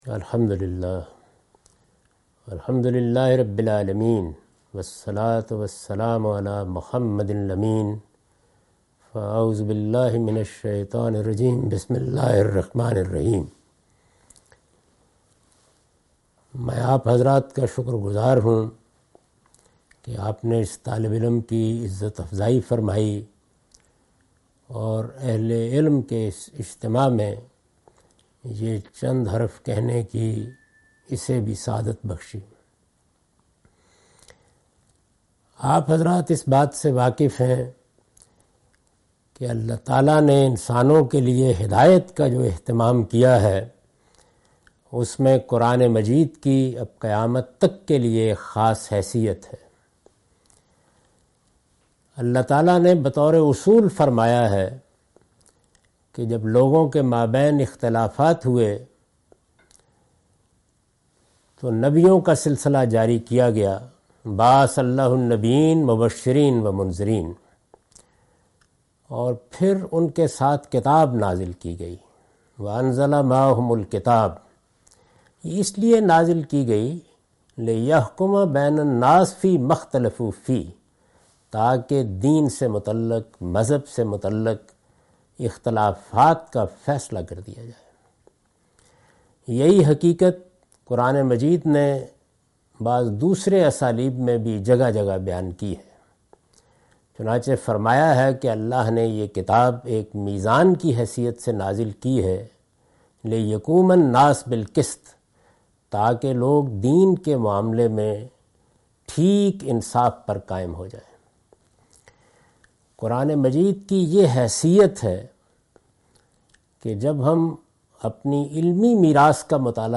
Javed Ahmed Ghamidi elaborates academic contributions of Farahi school of thought. (This video message was recorded for a conference held at Madrasatul Islah, India)